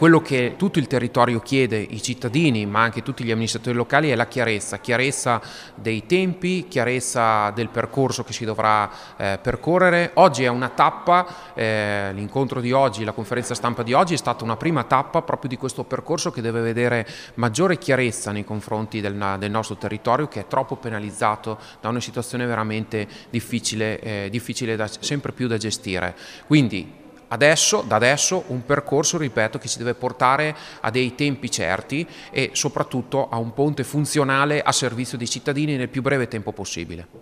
Ecco le dichiarazioni raccolte nella giornata dell’incontro: